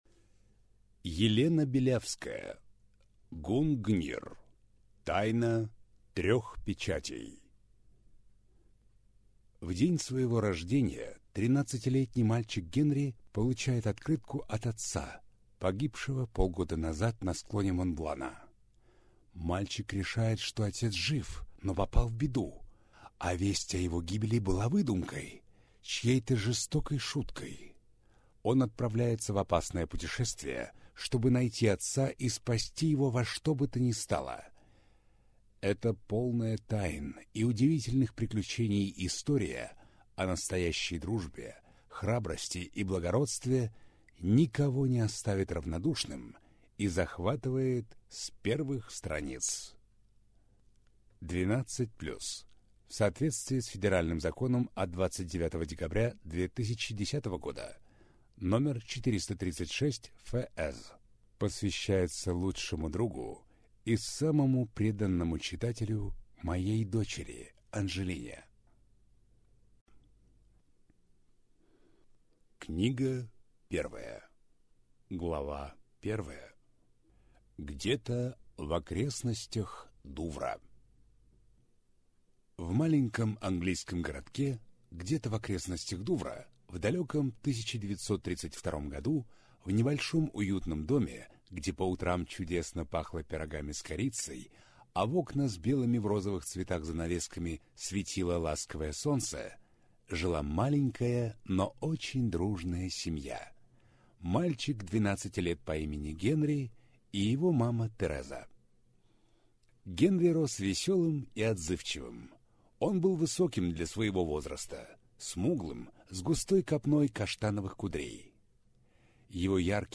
Аудиокнига Гунгнир. Тайна трёх печатей | Библиотека аудиокниг